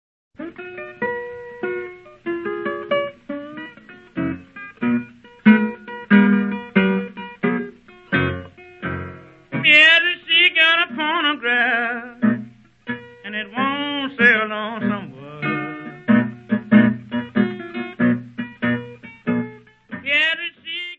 : stereo; 12 cm
Área:  Jazz / Blues